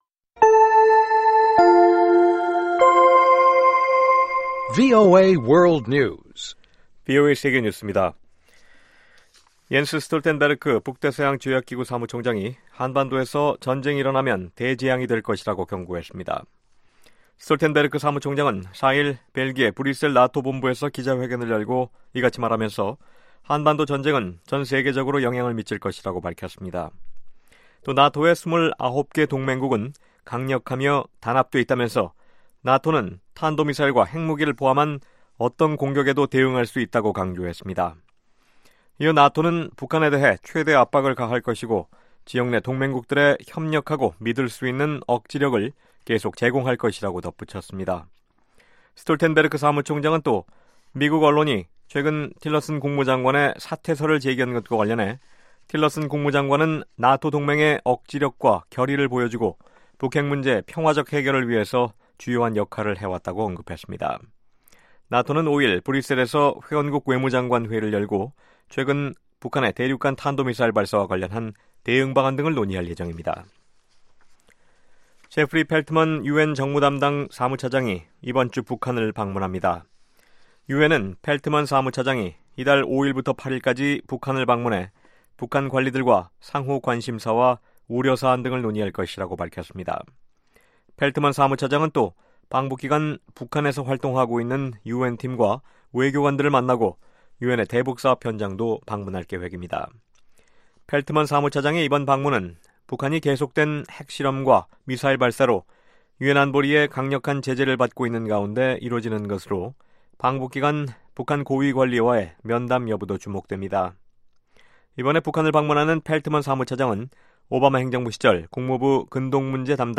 VOA 한국어 방송의 아침 뉴스 프로그램 입니다. 한반도 시간 매일 오전 5:00 부터 6:00 까지, 평양시 오전 4:30 부터 5:30 까지 방송됩니다.